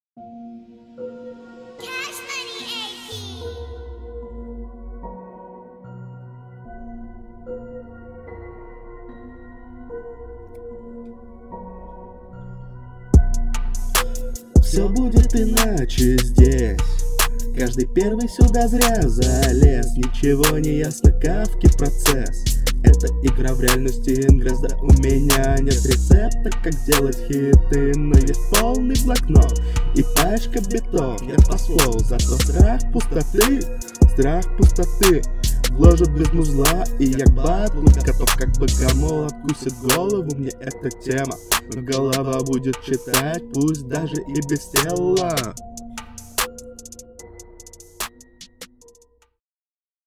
В начале ноты сомнительные. В целом исполнение ниже среднего, но есть зачатки стиля.